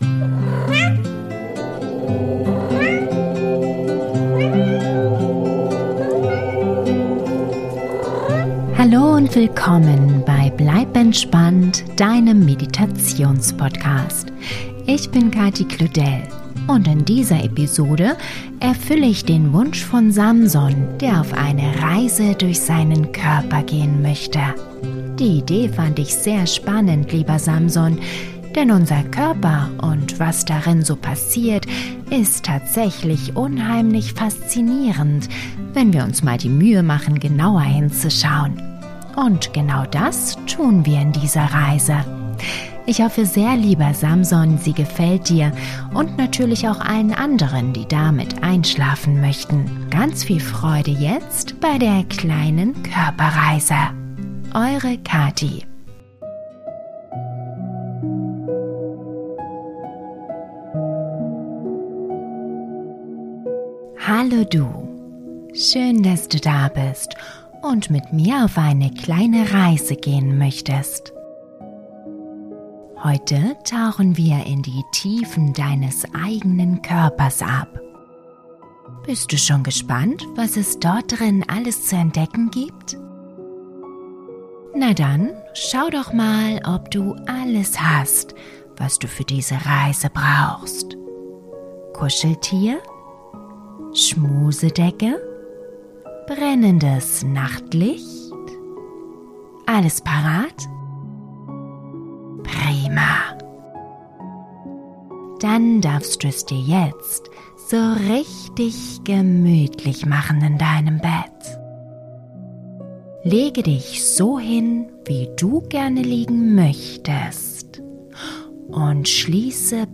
Traumreise für Kinder zum Einschlafen - Reise durch den Körper - Kindern erklärt ~ Bleib entspannt! Der Meditations-Podcast - magische Momente für Kinder & Eltern Podcast